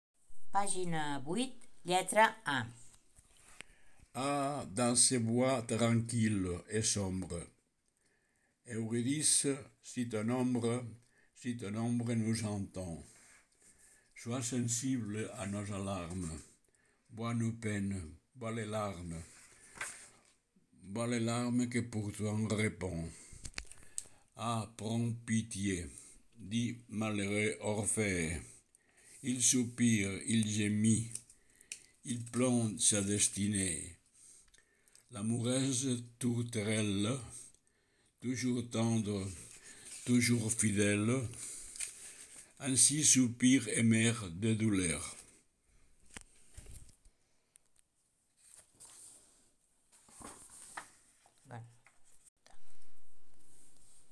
** AUDIOS PRONUNCIACIÓ TEXT**